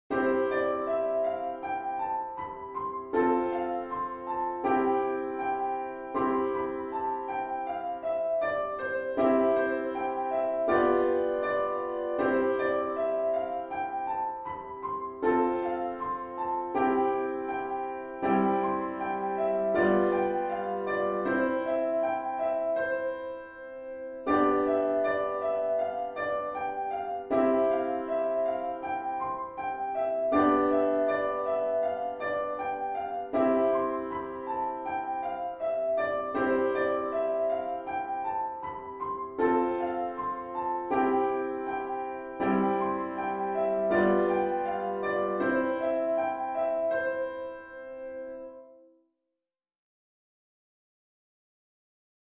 リピートは基本的に省略しています。